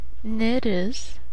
pronunciation) or Vilija (Belarusian: Ві́лія, romanizedVilija, Polish: Wilia) rises in northern Belarus.
Lt-Neris.oga.mp3